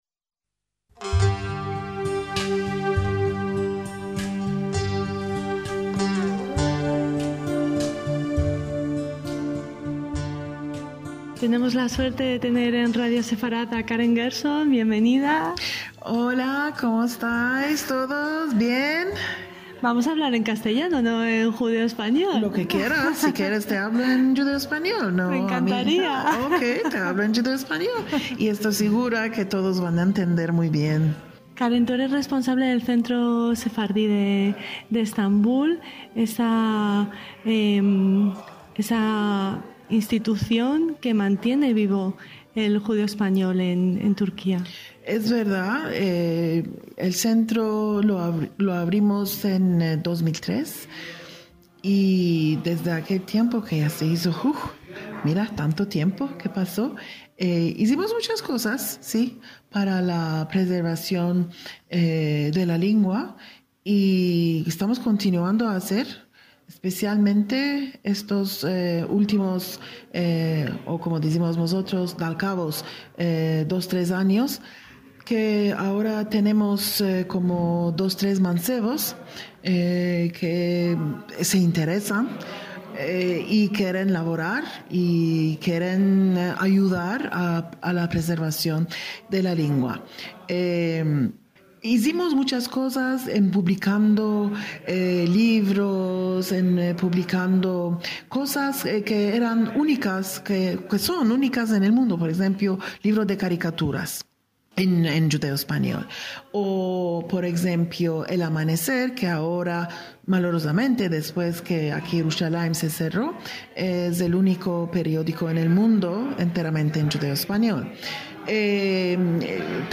DESDE LAS X JORNADAS SEFARDÍES EN LA RIOJA